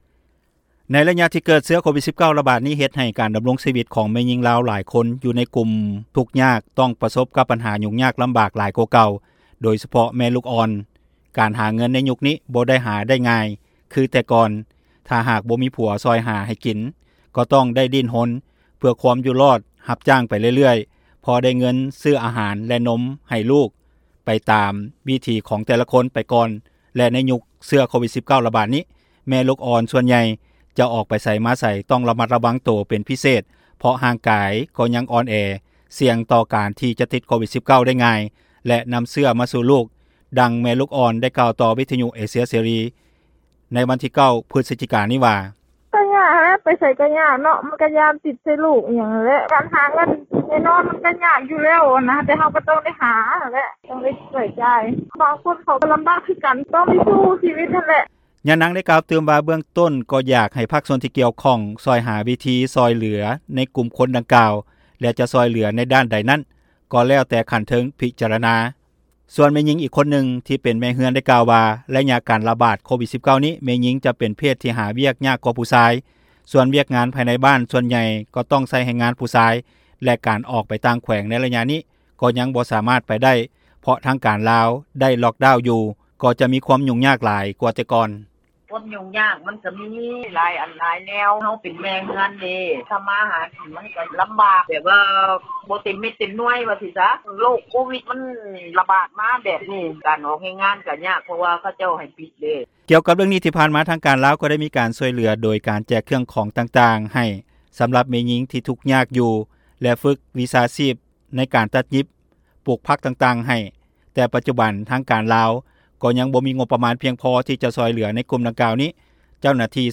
ດັ່ງແມ່ລູກອ່ອນໄດ້ກ່າວຕໍ່ ວິທຍຸຸເອເຊັຽເສຣີ ໃນວັນທີ 9 ພຶສຈິກາ ນີ້ວ່າ:
ສ່ວນແມ່ຍິງອີກຄົນນຶ່ງທີ່ເປັນແມ່ເຮືອນໄດ້ກ່າວວ່າ ໄລຍະນີ້ແມ່ຍິງຈະເປັນເພດທີ່ ຫາວຽກຍາກກວ່າຜູ້ຊາຍ.